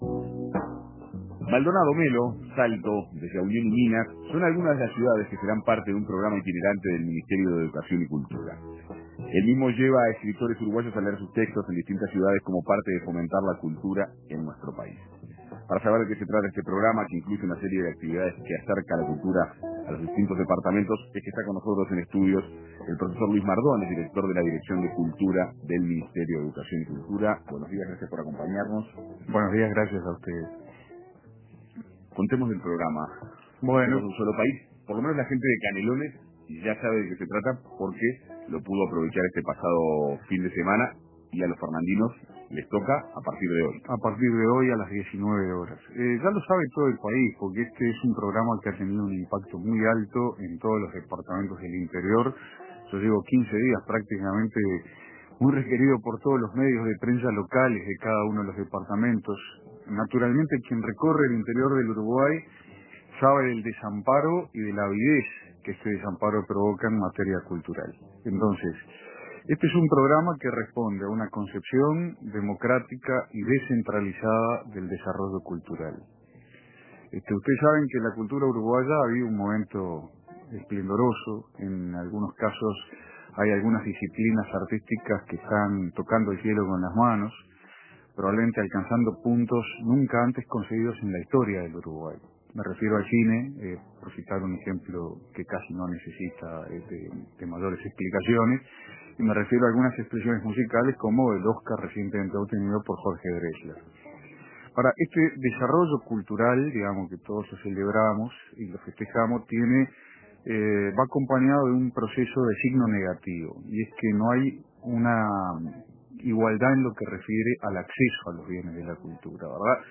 El director de Cultura, Luis Mardones, comenta el programa que difunde escritores nacionales por el interior del país